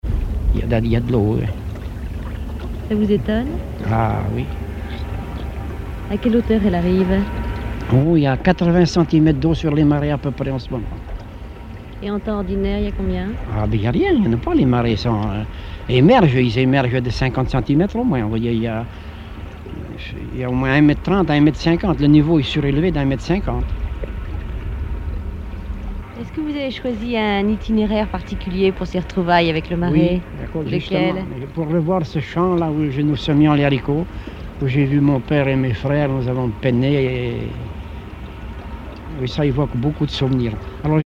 Emisson Départementale, sur Radio France Culture
Catégorie Témoignage